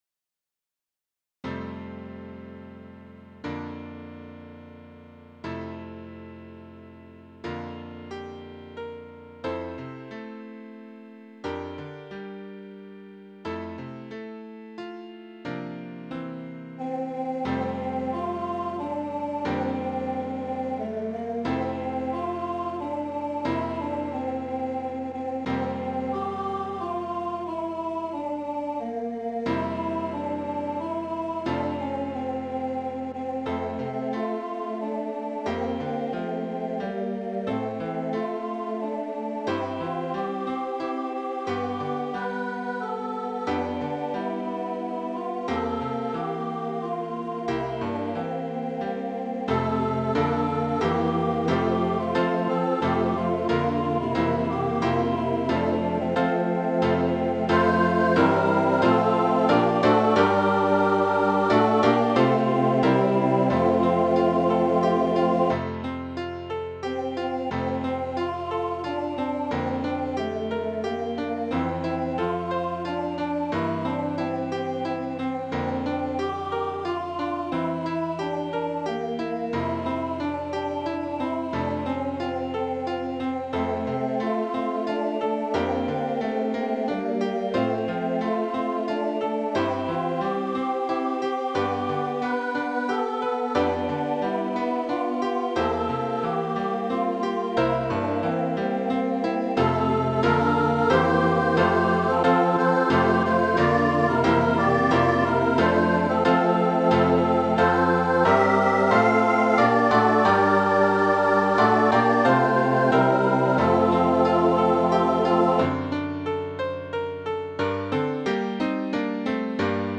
Voicing/Instrumentation: Vocal Solo We also have other 55 arrangements of " I Stand All Amazed ".